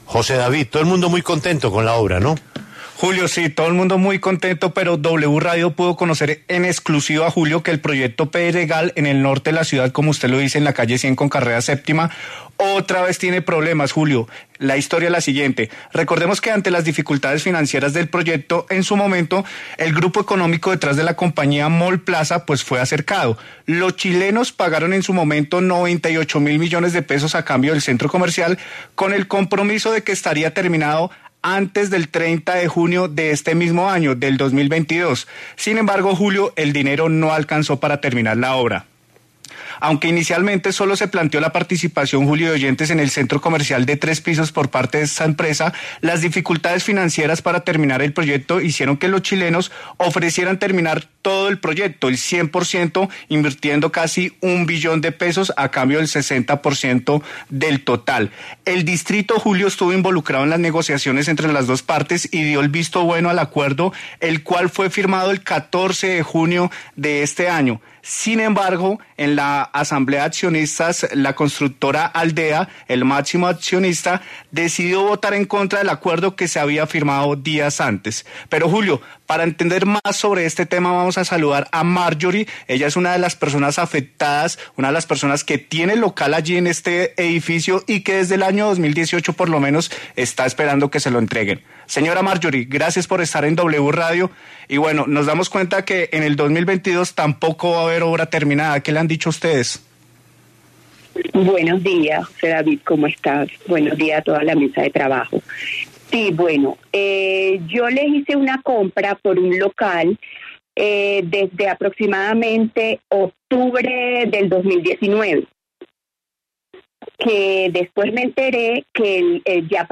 Los afectados